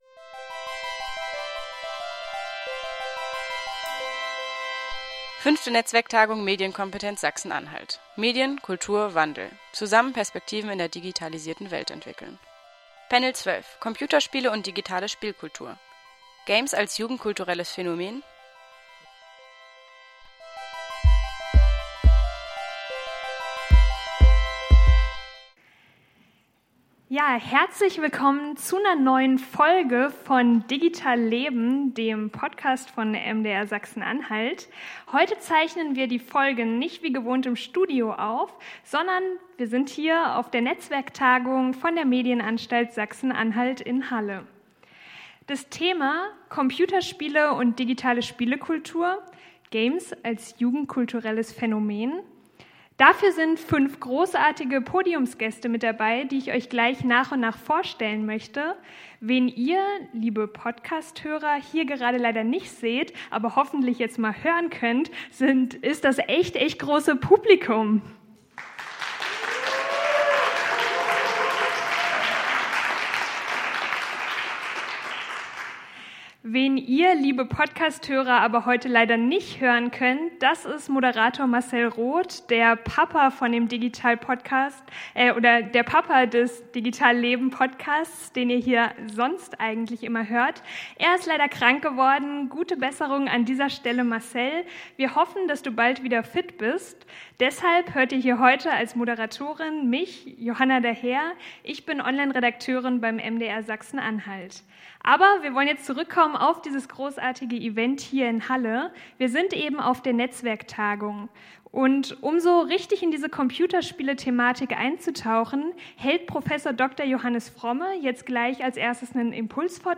Panel_12_Digitale Spielekultur.mp3